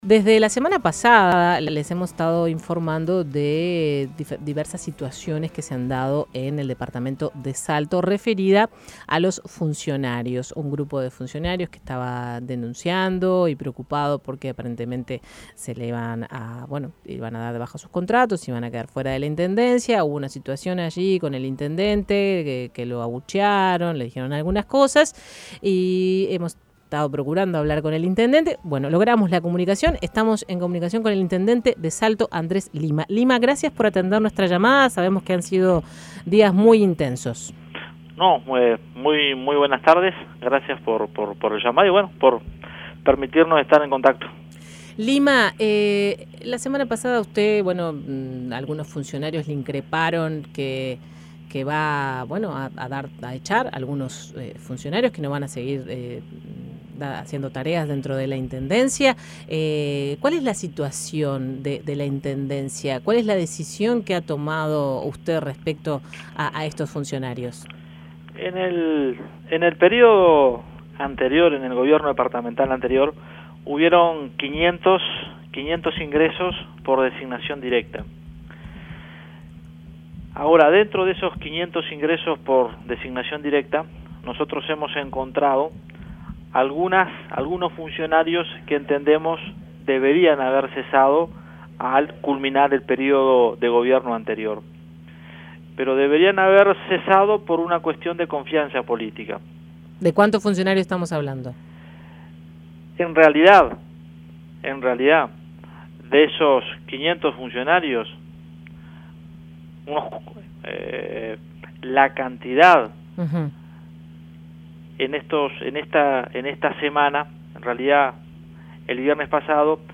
Escuche la entrevista con Andrés Lima